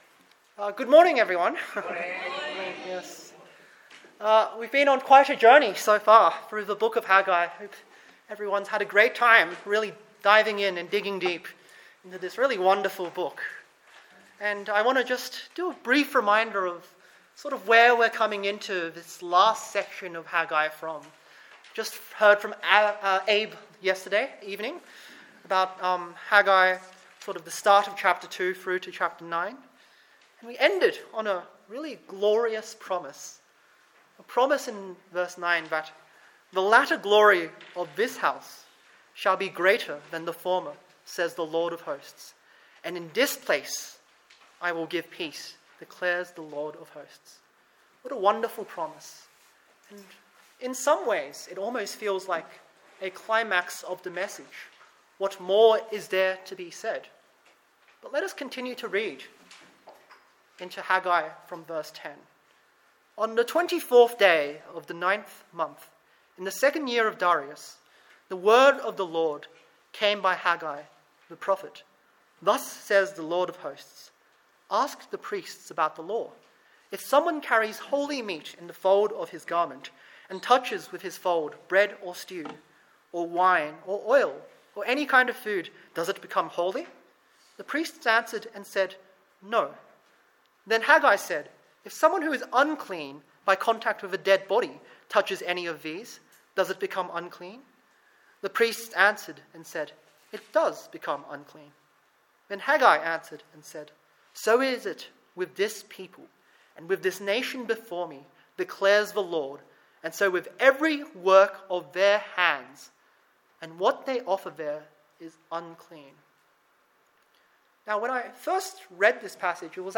The Young Adults Camp was held from Friday July 8 (evening) to Sunday July 10 at Hartley, a KCC site in Katoomba, a great site with really excellent service and amazing food!